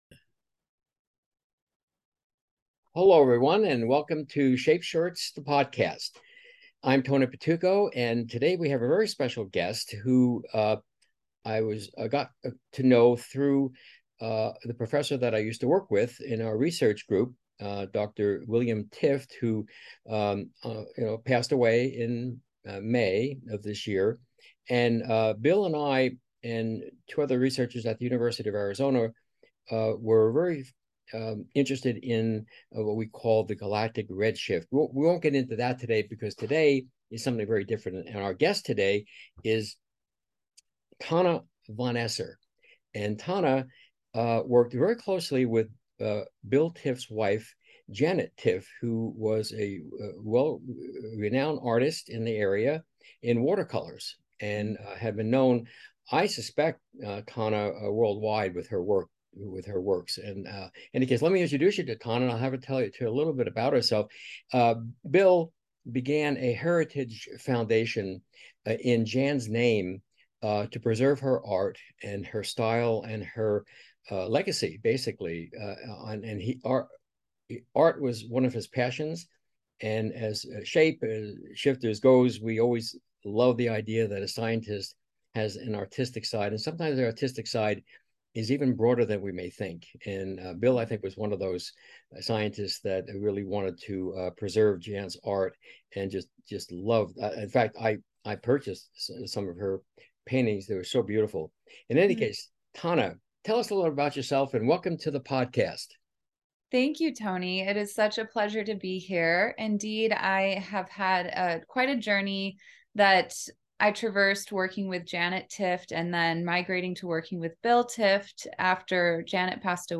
2023 Podcast Interview